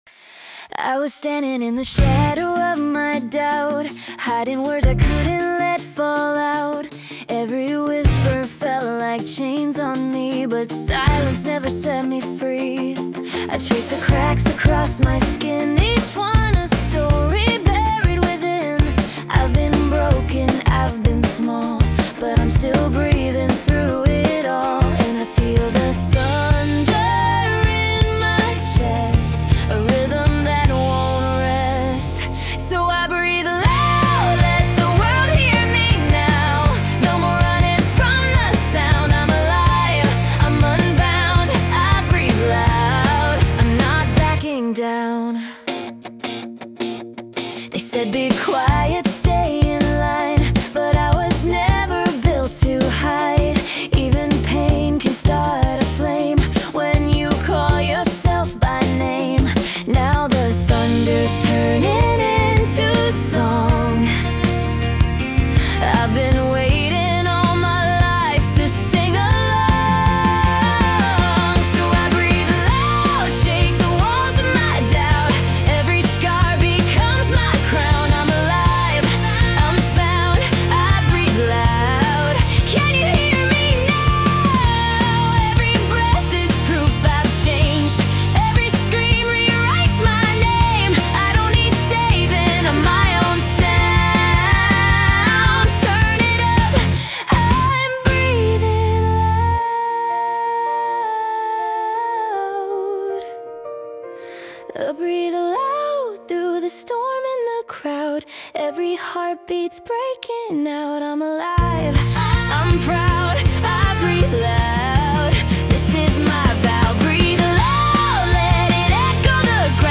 洋楽女性ボーカル著作権フリーBGM ボーカル
著作権フリーオリジナルBGMです。
女性ボーカル（洋楽・英語）曲です。